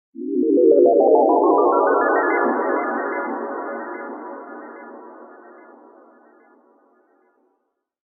Magical Riser Sound Effect For Fantasy Transitions And Spell Build-Ups
Fantasy spell riser with cinematic whoosh and ethereal build-up.
Genres: Sound Effects
Magical-riser-sound-effect-for-fantasy-transitions-and-spell-build-ups.mp3